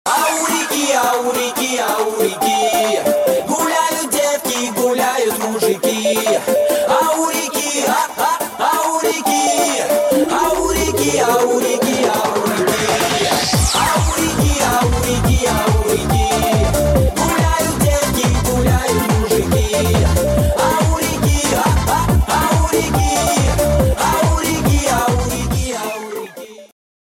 • Качество: 128, Stereo
поп
забавные
веселые